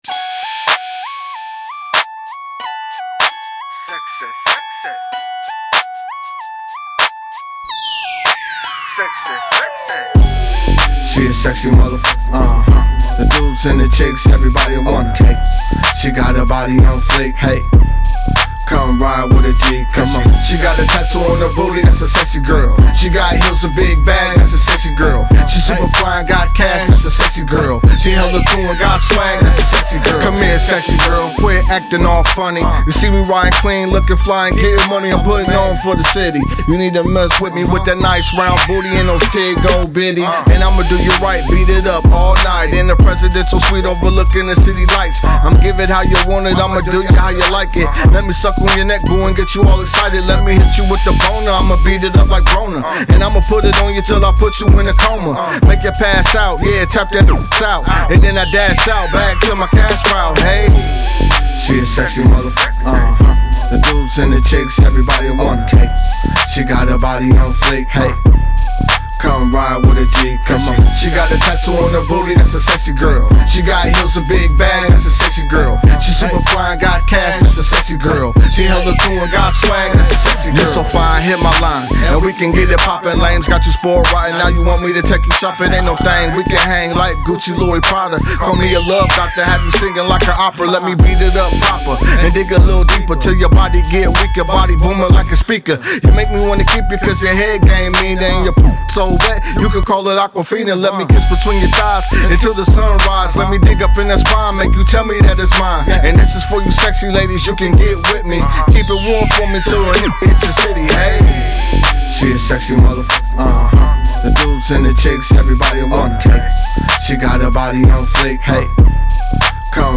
Description : Club Banger